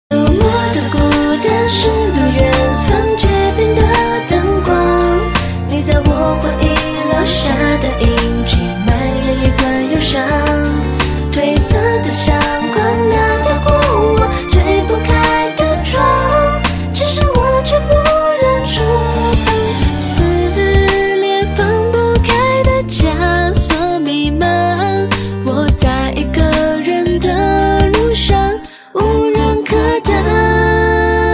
M4R铃声, MP3铃声, 华语歌曲 106 首发日期：2018-05-15 08:58 星期二